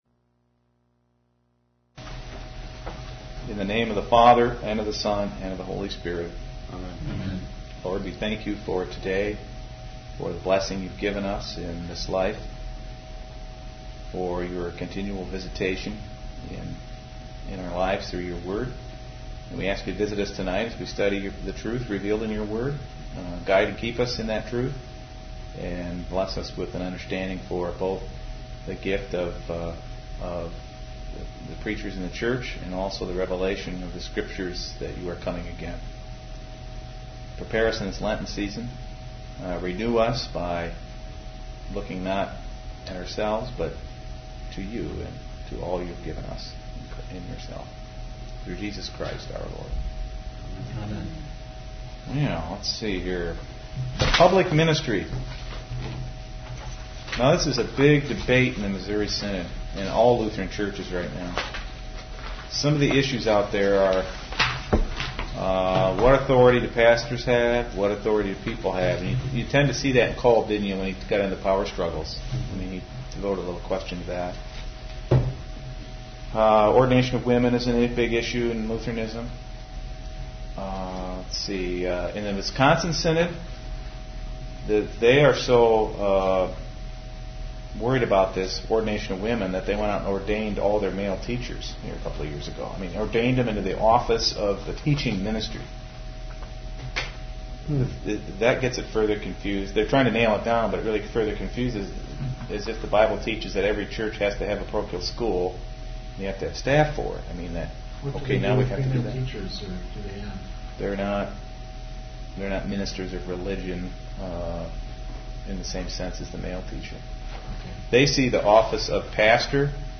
Lutheran Doctrine Class - Last Class